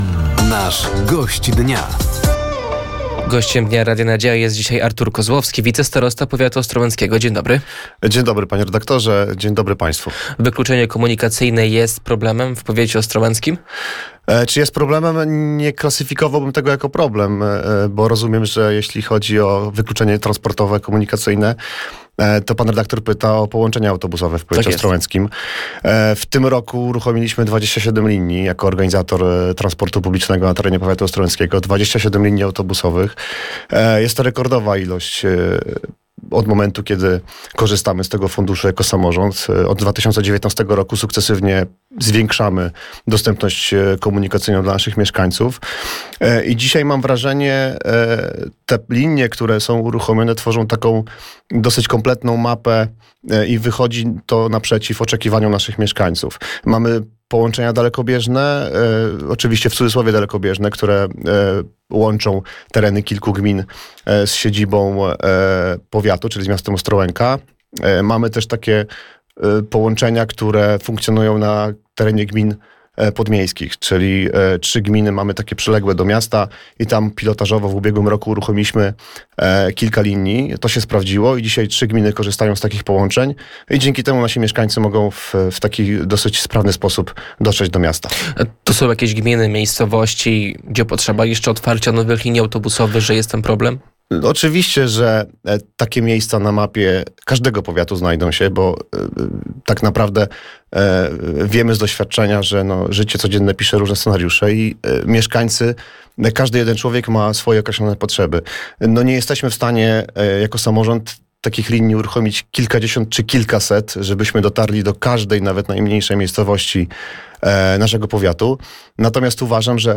Gościem Dnia Radia Nadzieja był wicestarosta ostrołęcki Artur Kozłowski. Tematem rozmowy był transport publiczny na terenie powiatu, II Forum Edukacji i Rynku Pracy, sytuacja szkół w powiecie i gminach oraz CPK.